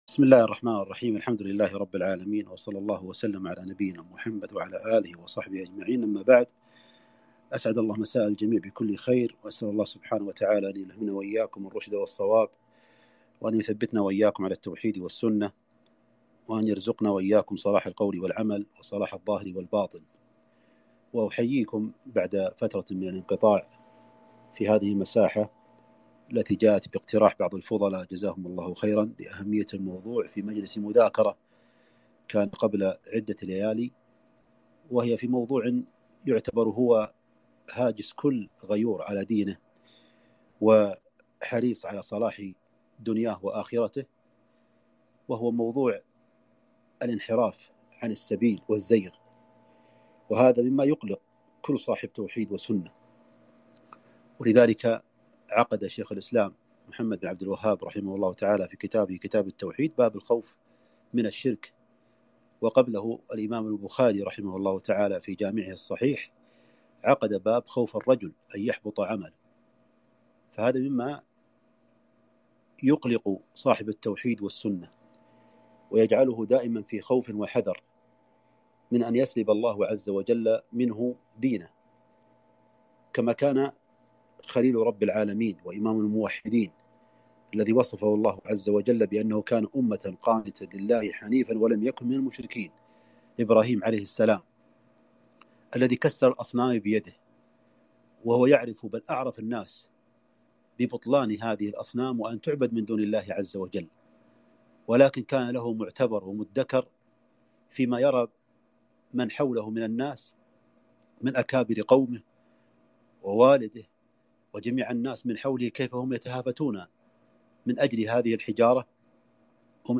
محاضرة - أسباب الإنحراف والزيغ عن الطريق